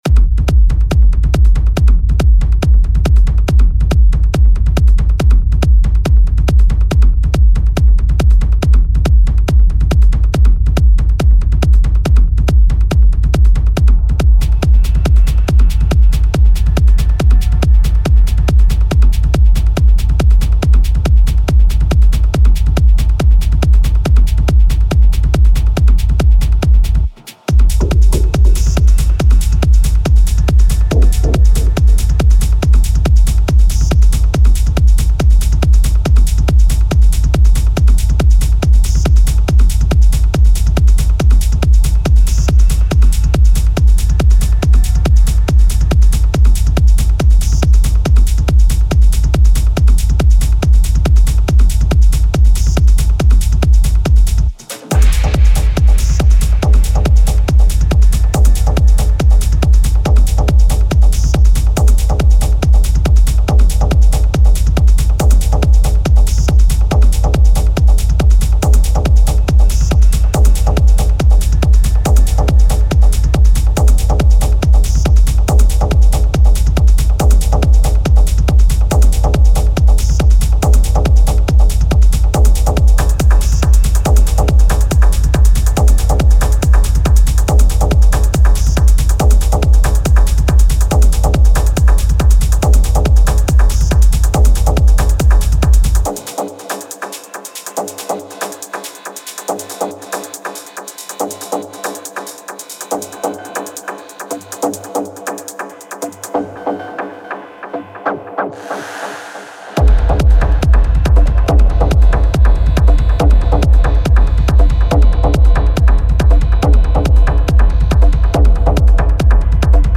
Techno and house music, deep bass and fast beats.